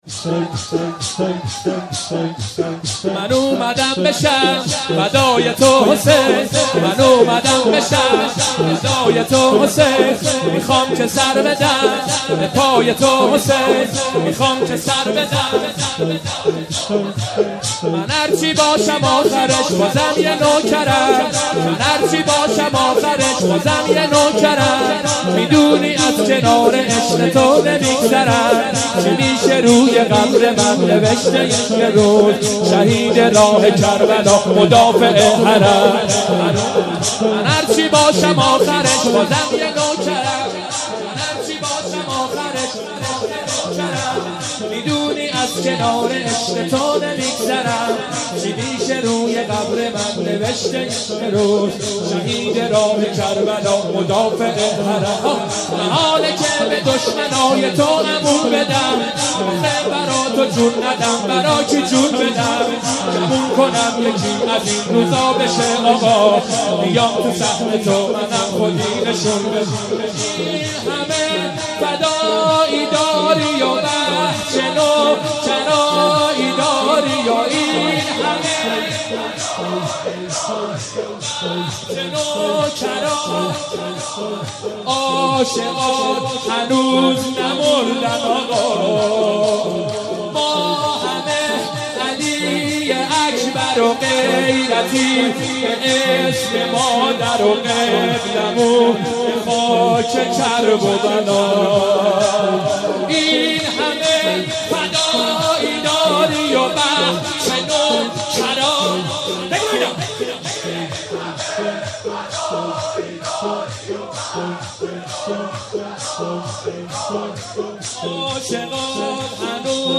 شور کربلایی